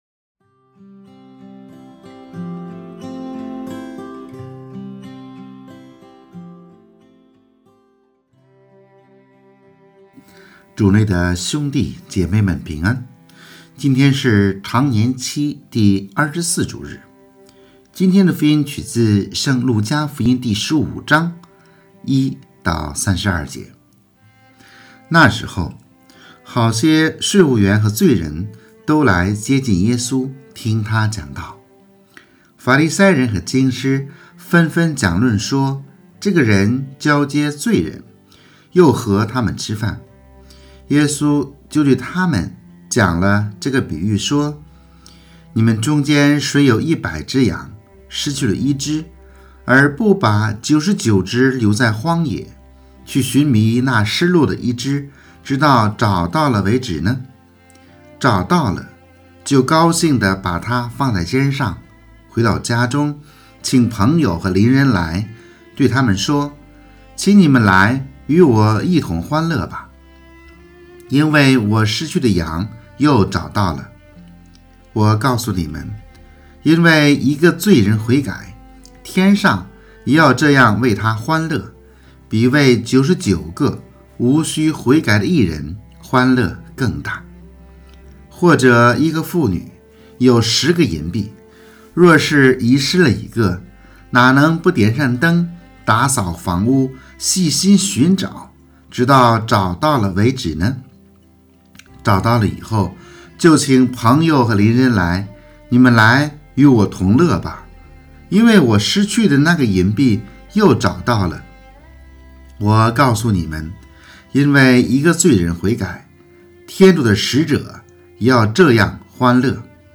【主日证道】|每一个灵魂都是高贵的（丙-常24主日）